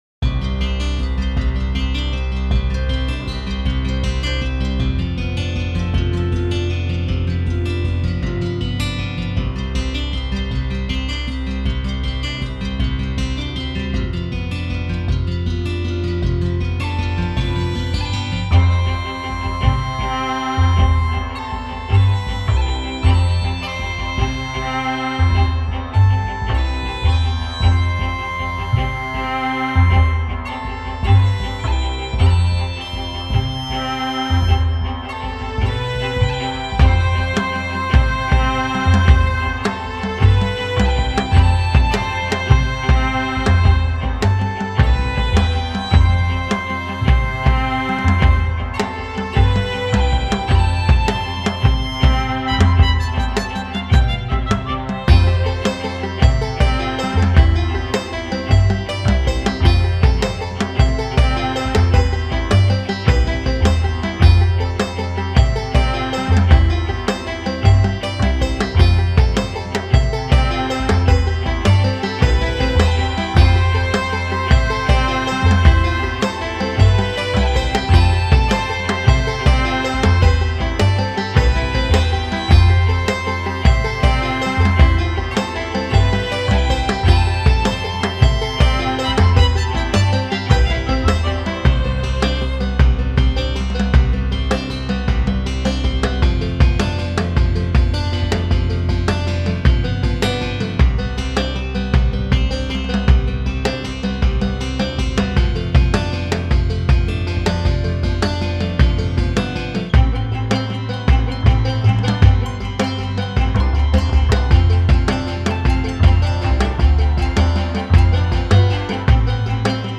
Tumbles through green glades into pools of blue light